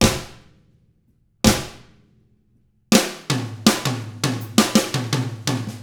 164ROCK I1-R.wav